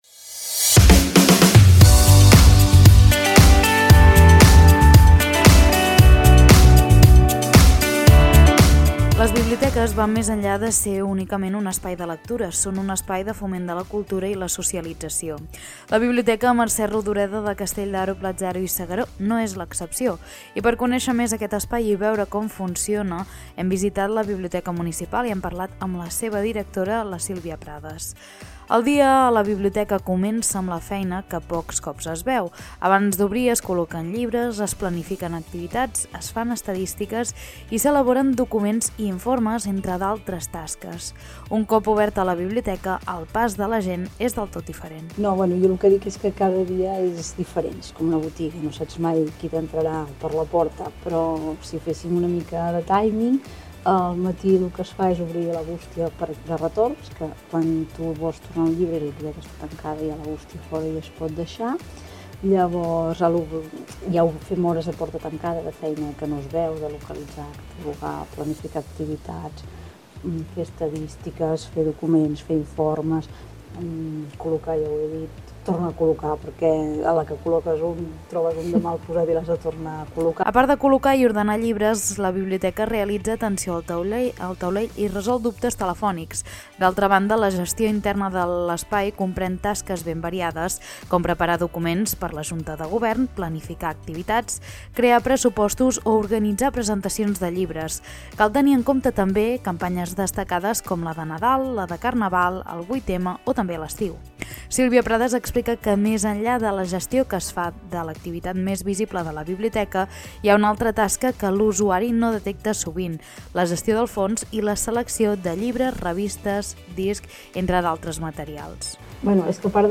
Reportatges